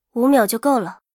尘白禁区_安卡希雅语音_出现1.mp3